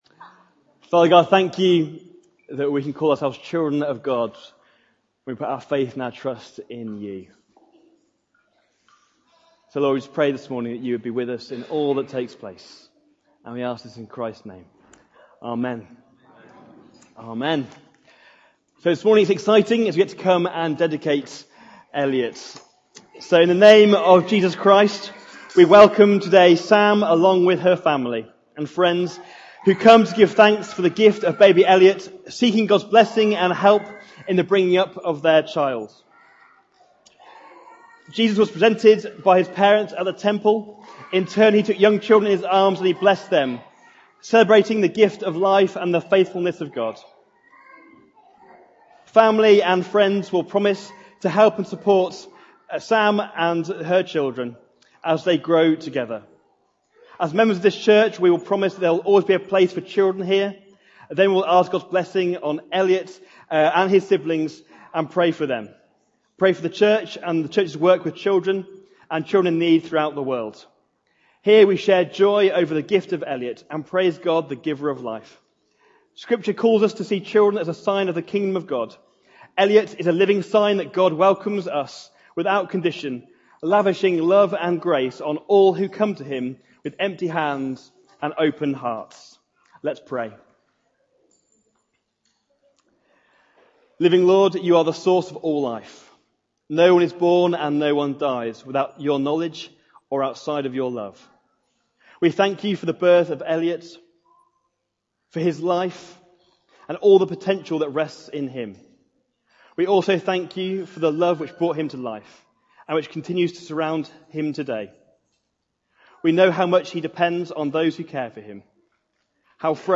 Sep 02, 2019 Extravagant Generosity Summer 2019 MP3 SUBSCRIBE on iTunes(Podcast) Notes Discussion Sermons in this Series First 10 mins includes Dedication Service Readings:- 2 Kings 4:1-7, Matthew 6:21 Loading Discusson...